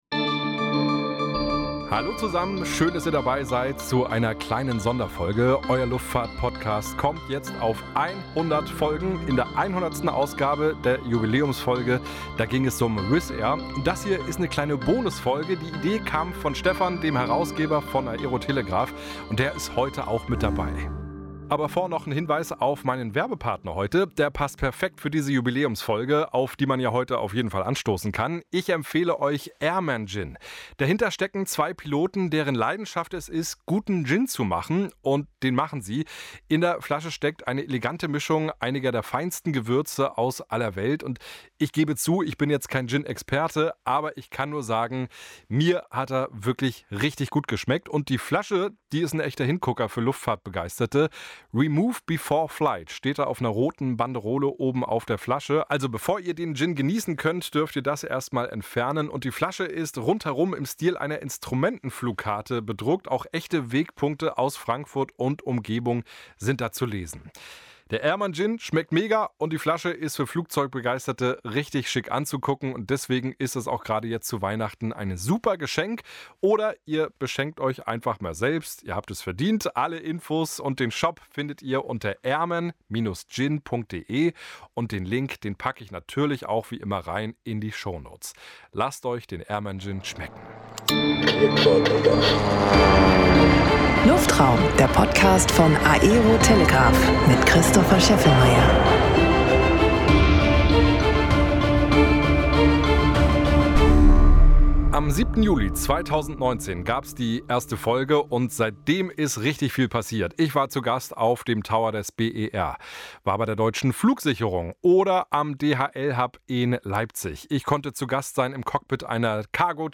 In dieser Jubiläumsfolge bekomme ich einmal die Fragen gestellt.